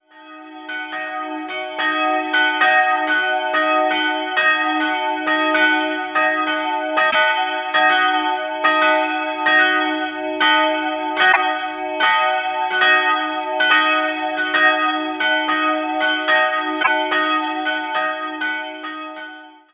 Im Jahr 1989 schenkte die katholische Kirchengemeinde Freystadt das Gotteshaus der evangelischen Kirchengemeinde. 2-stimmiges Kleine-Terz-Geläute: dis''-fis'' Die beiden Glocken wurden im Jahr 1991 gegossen, der Gießer ist mir nicht bekannt.